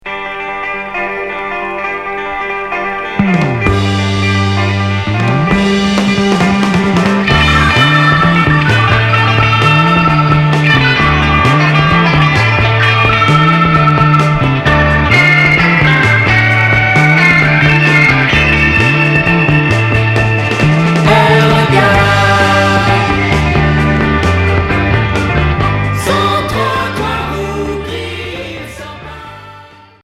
Pop psychédélique Unique 45t retour à l'accueil